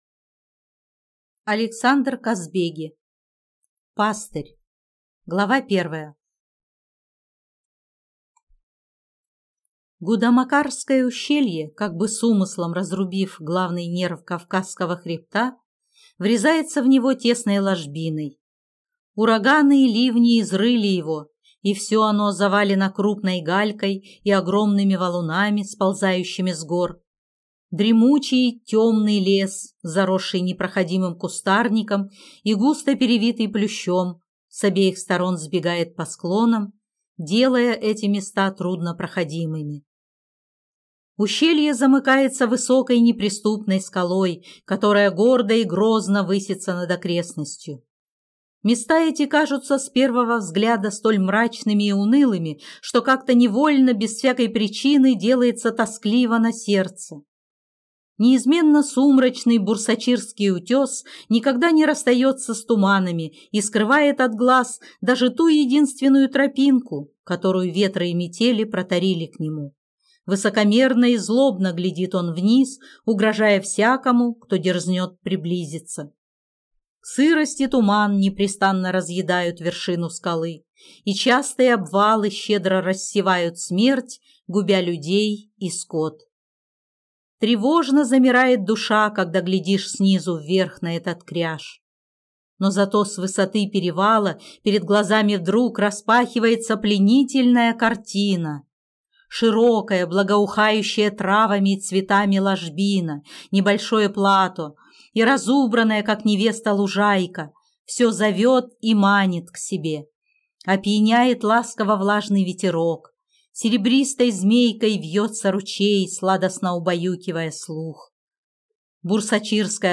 Аудиокнига Пастырь | Библиотека аудиокниг